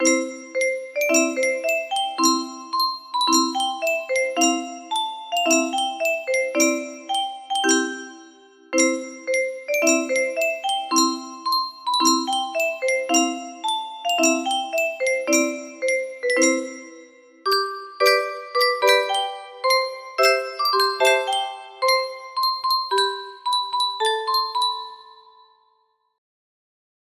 Clone of Scotland the Brave music box melody